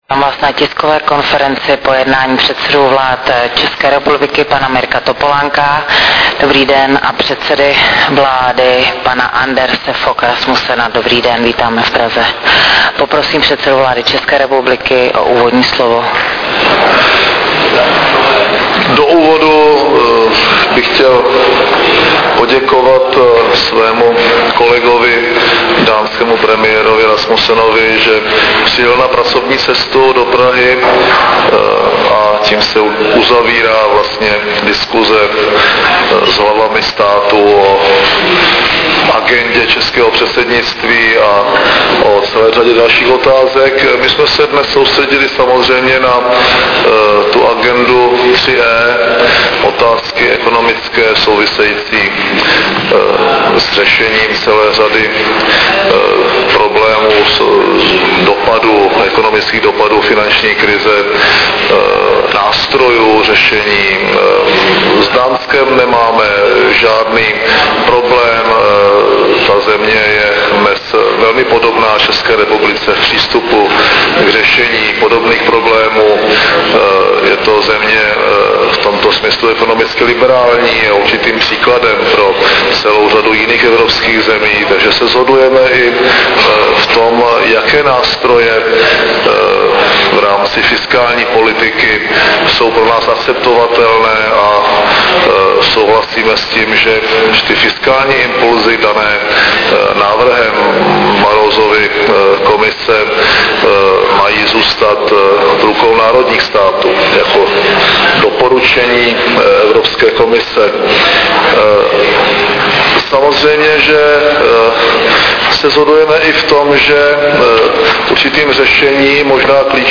Tisková konference premiéra M. Topolánka a předsedy vlády Dánského království Anderse Fogh Rasmussena 9.12.2008